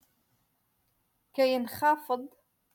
Moroccan Dialect - Rotation Two- Lesson Fifty One